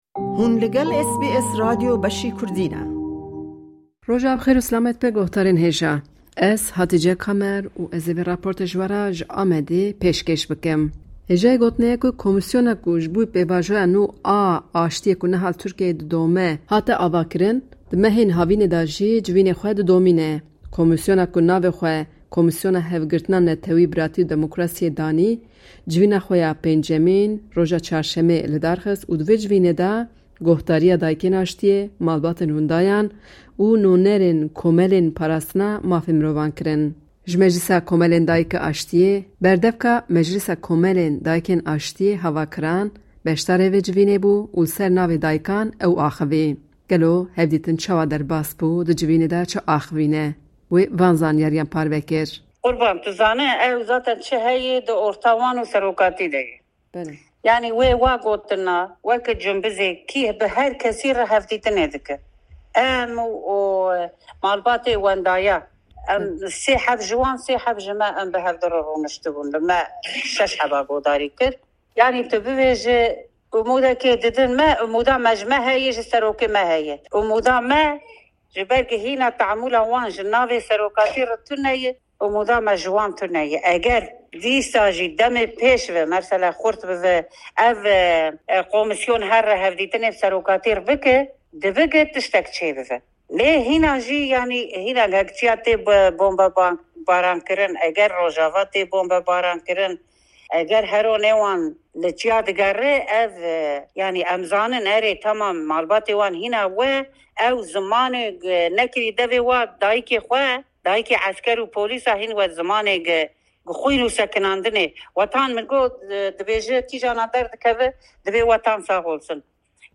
Wê jibo SBSê behsa naveroka civînê kir.